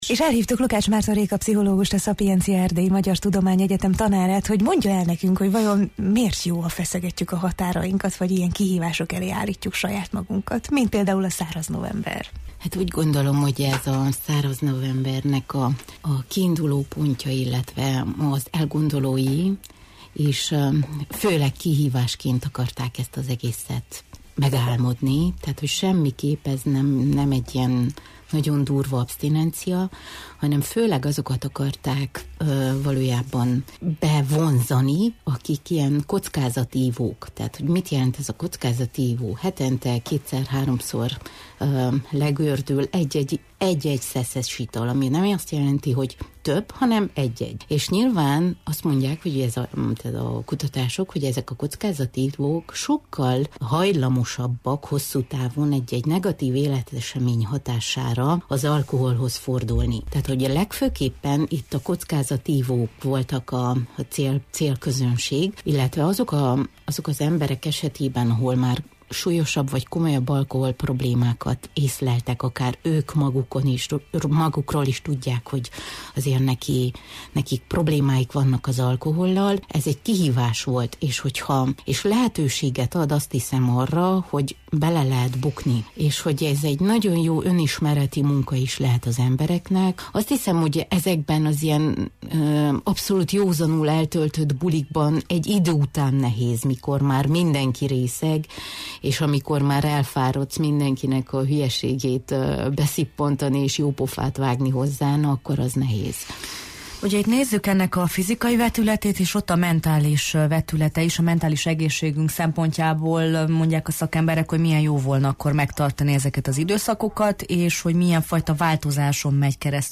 A kihívás fontosságáról kérdeztük a szakembert a Jó reggelt, Erdély!-ben: